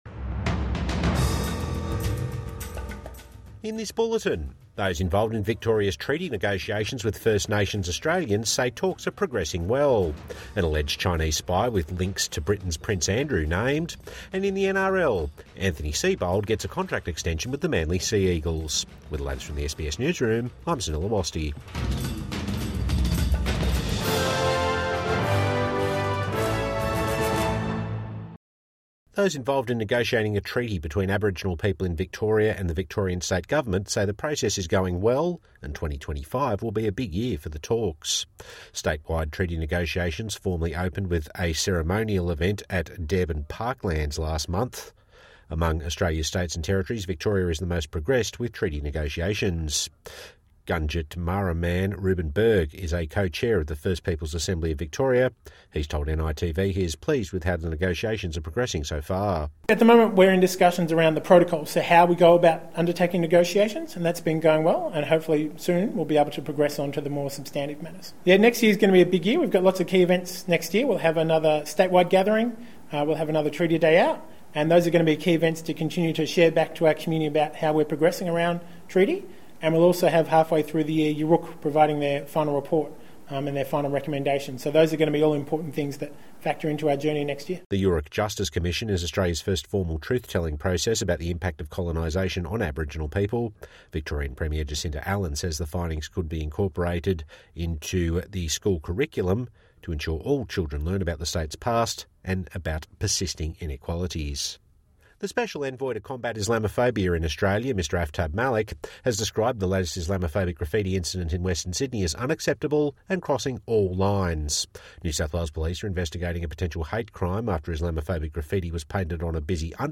Midday News Bulletin 17 December 2024